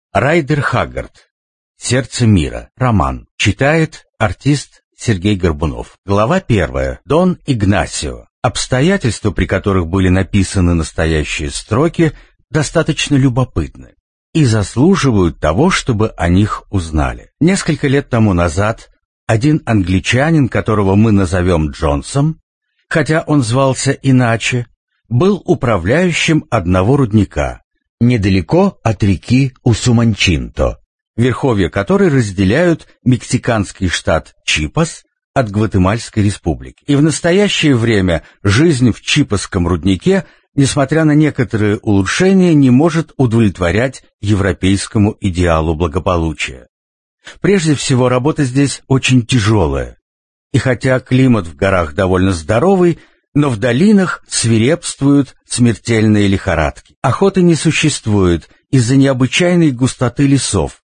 Аудиокнига Сердце Мира | Библиотека аудиокниг